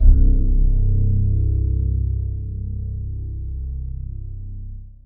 Index of /90_sSampleCDs/Zero G Creative Essentials Series Vol 26 Vintage Keyboards WAV-DViSO/TRACK_17